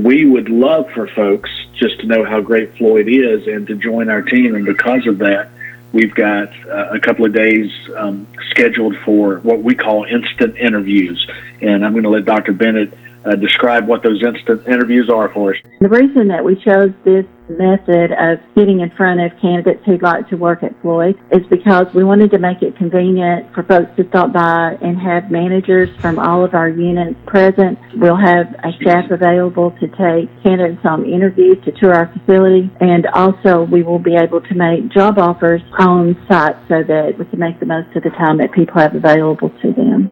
Tuesday’s edition of “Live Well Cherokee” included some very important information for those seeking jobs in the medical field, with on-site interviews being conducted at Atrium Health Floyd in Rome, today and tomorrow (Wednesday and Thursday, April 27th and 28th).